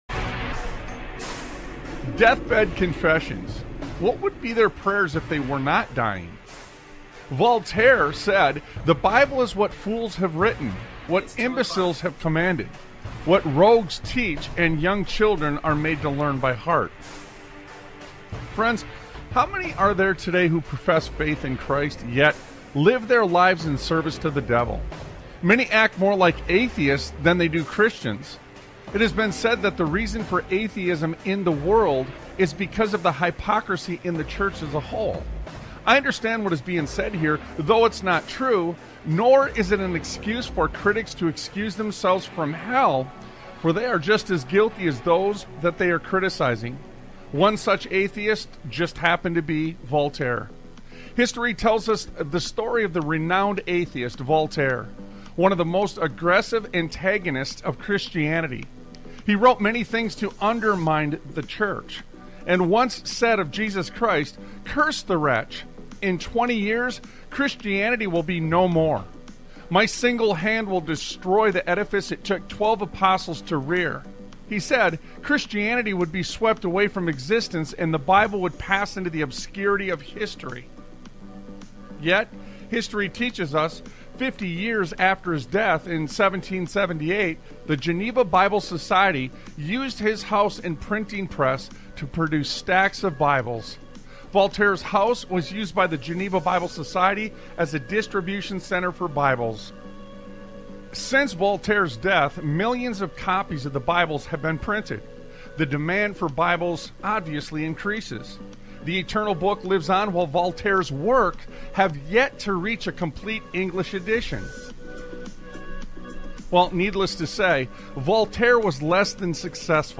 Talk Show Episode, Audio Podcast, Sons of Liberty Radio and And THEY (Doctors) Are Just Baffled on , show guests , about And THEY Doctors Are Just Baffled, categorized as Education,History,Military,News,Politics & Government,Religion,Christianity,Society and Culture,Theory & Conspiracy